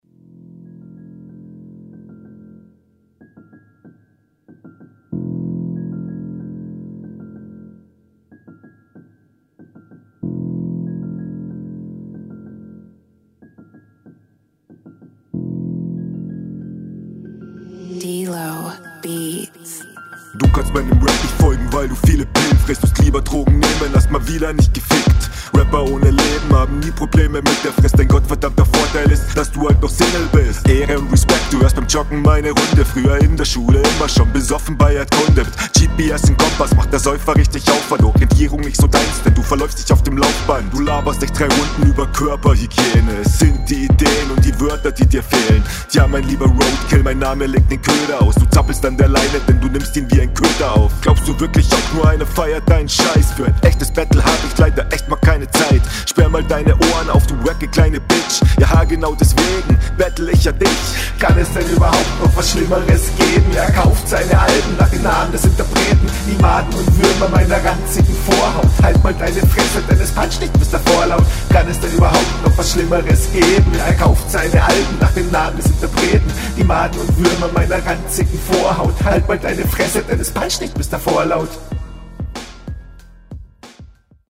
Warum hast du alle Runden eine andere Stimme haha.
deine stimmlage ist irgendwie cool. auch dir fehlts da noch an der routine wodurch ich …